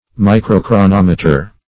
Microchronometer \Mi`cro*chro*nom"e*ter\, n.